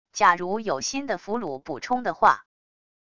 假如有新的俘虏补充的话wav音频生成系统WAV Audio Player